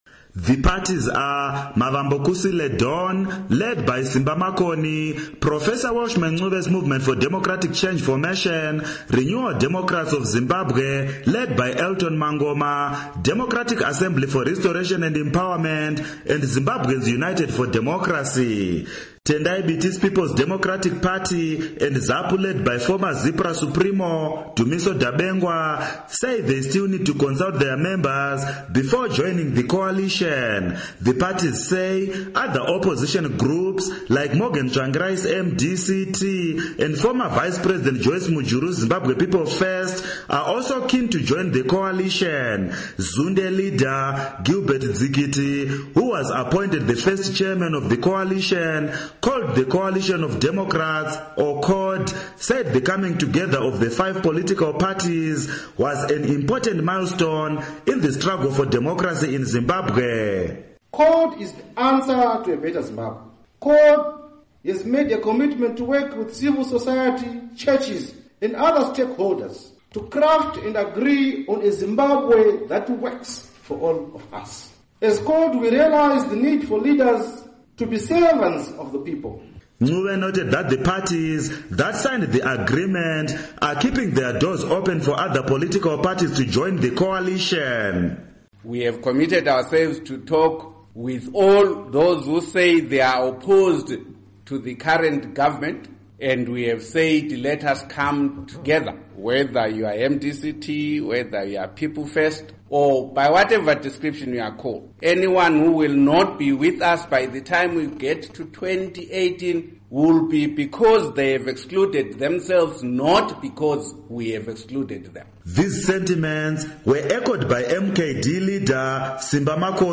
Report on Opposition Coalition